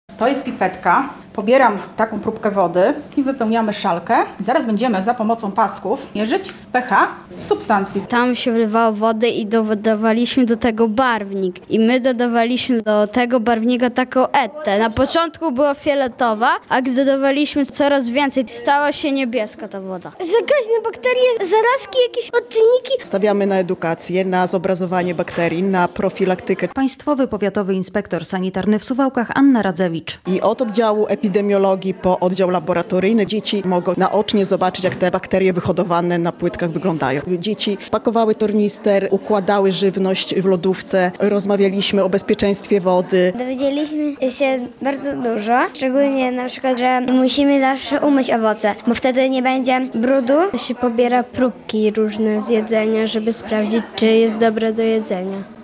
Dzień otwarty w suwalskim sanepidzie - relacja
Choć motywem przewodnim spotkania była nauka prawidłowego mycia rąk, to nie zabrakło innych naukowych atrakcji - podkreśla Państwowy Powiatowy Inspektor Sanitarny w Suwałkach Anna Radzewicz.